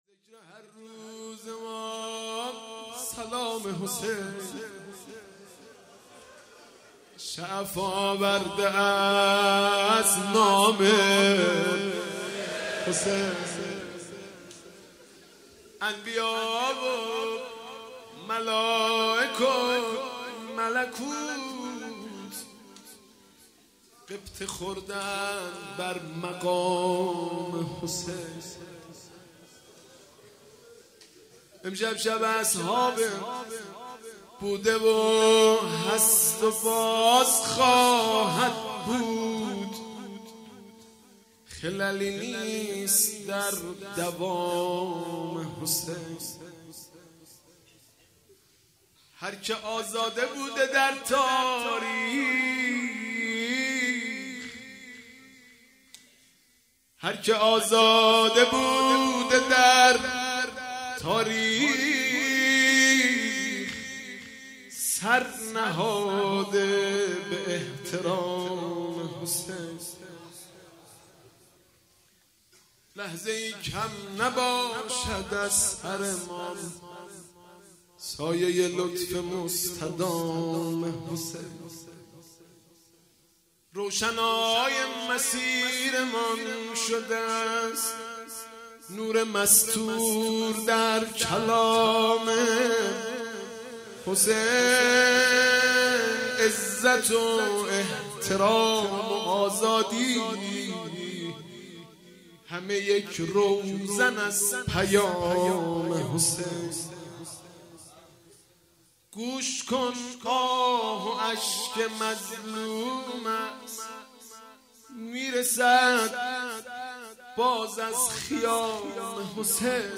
خیمه گاه - حاج مهدی رسولی - بخش دوم - روضه (روضه عبدالله ابن حسن علیه السلام)
شب چهارم محرم 1397